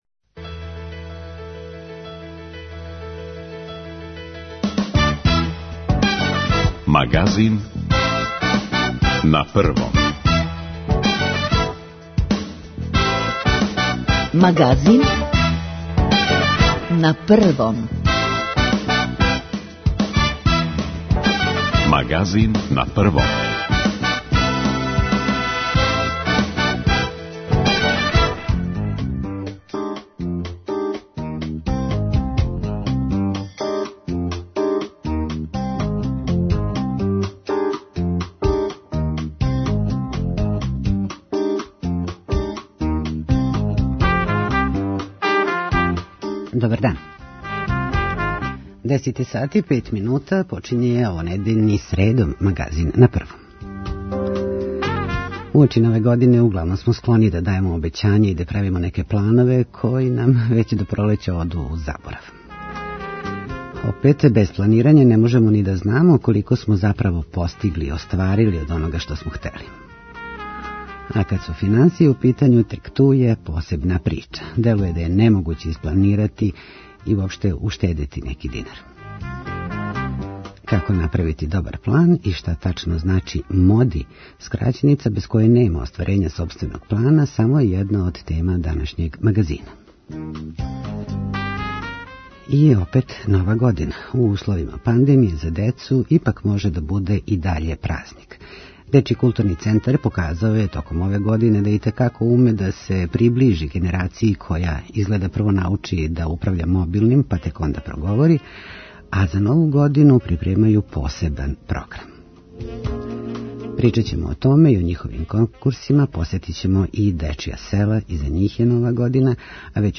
Наши дописници се јављају из Грчке, Словачке и Летоније.